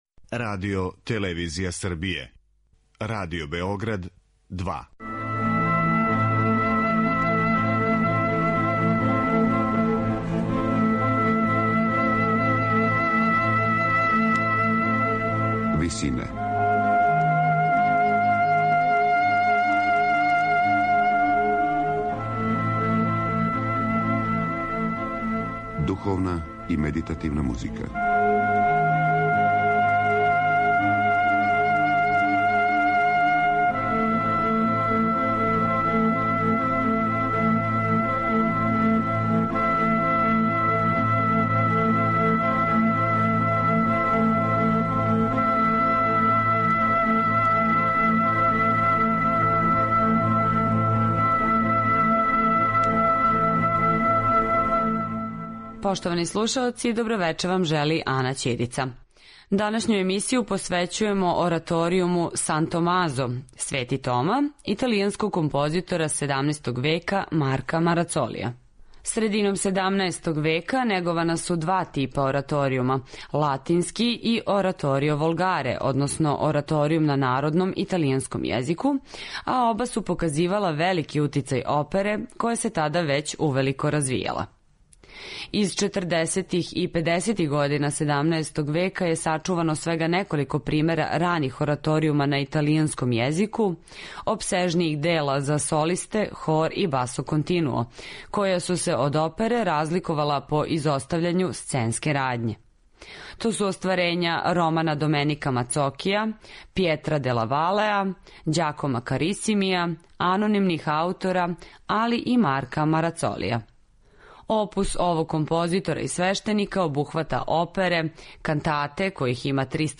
Емисија духовне и медитативне музике Радио Београда 2 овога пута је посвећена ораторијуму „Свети Тома" италијанског композитора из XVII века Марка Марацолија.